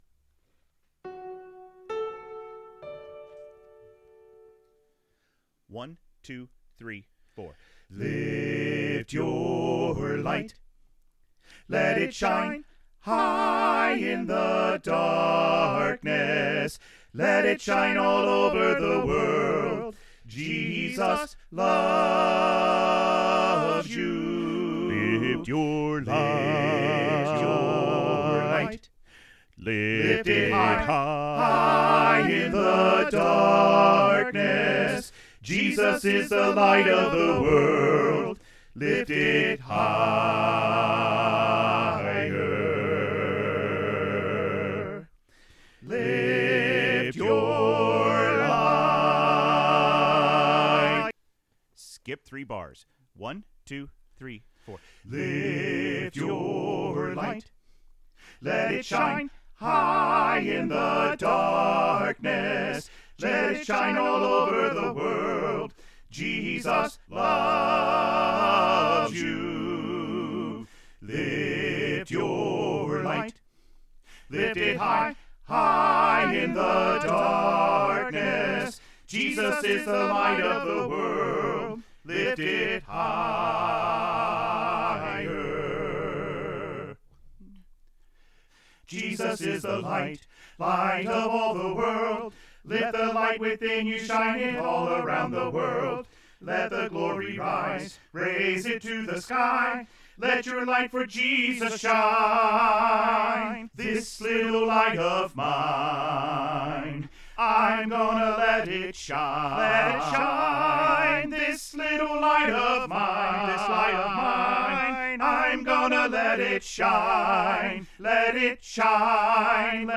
Choir Music Learning Recordings
Lift Your Light - Even Mix Even Mix of all 4 Parts